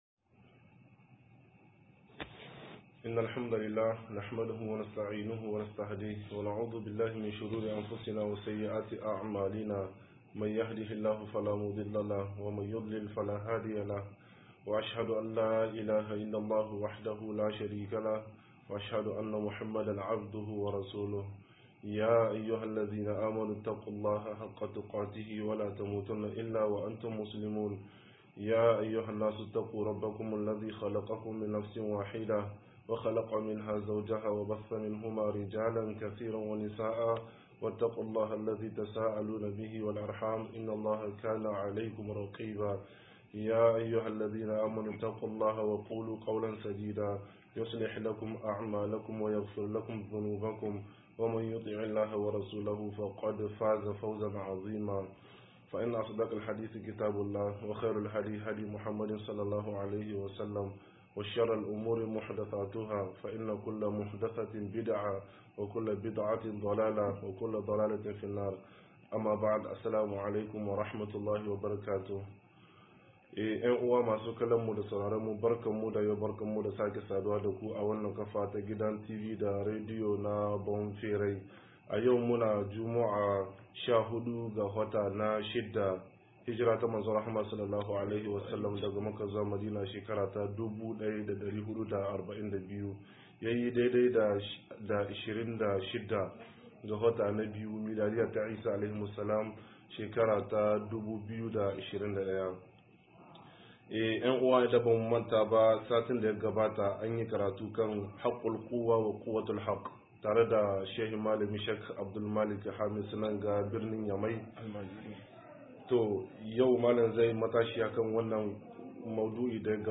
117-Yakar hankula 2 - MUHADARA